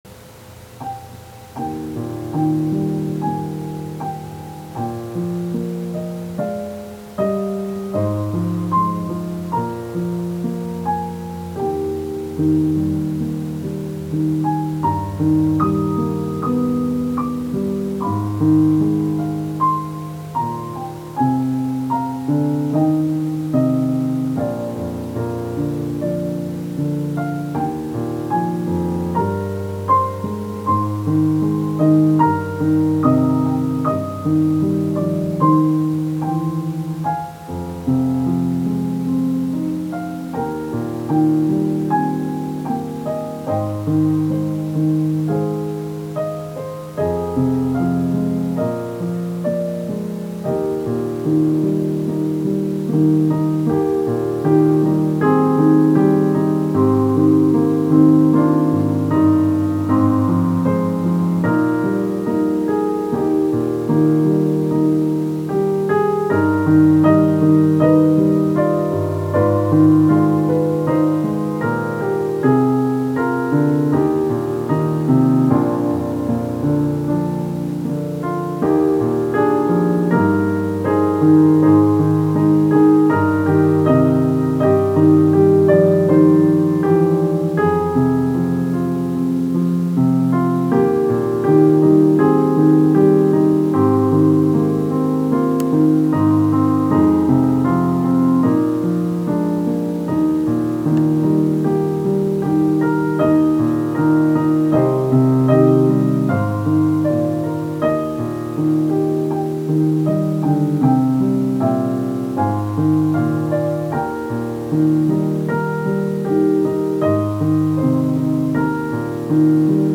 Here is an arrangement with two verses of the hymn and one verse of Fairest Lord Jesus that I included at the end.